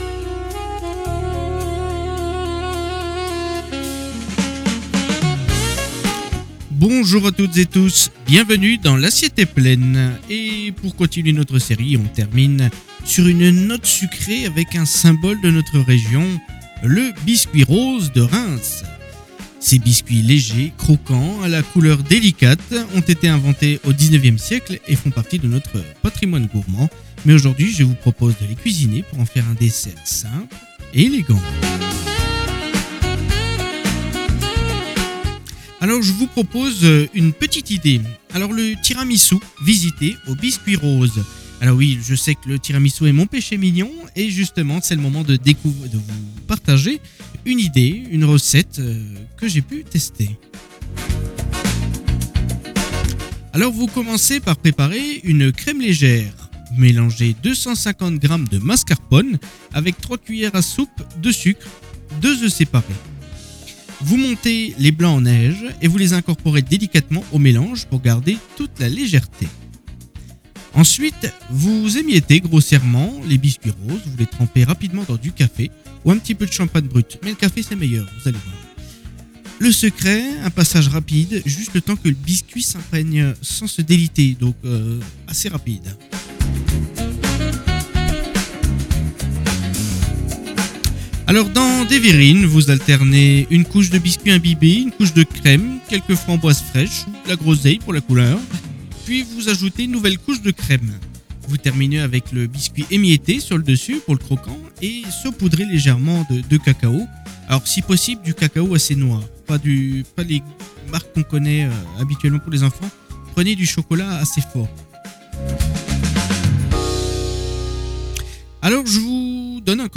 Chaque semaine sur Antenne 87, partez à la découverte d’un plat typique ou d’une recette oubliée des Plaines de Champagne, dans la chronique culinaire "L’Assiette est Plaines".
Une chronique gourmande avec les idées du terroir